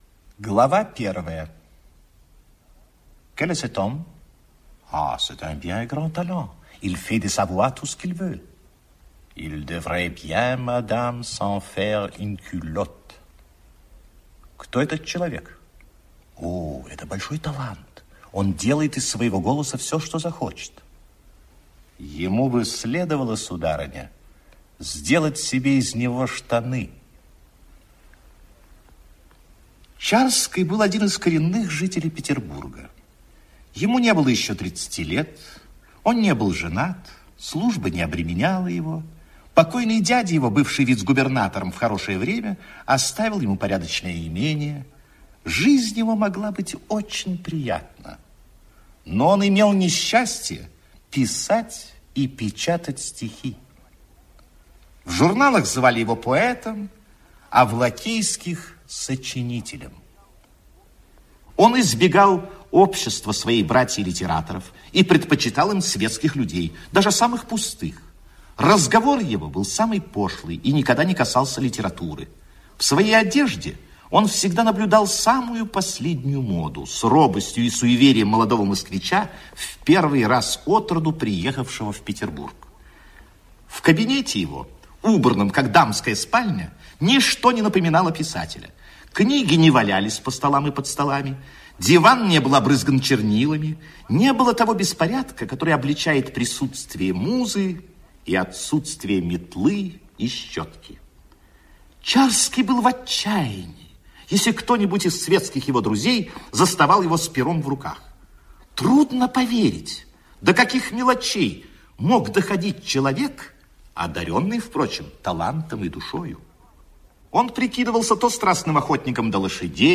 Египетские ночи - аудио повесть Пушкина - слушать онлайн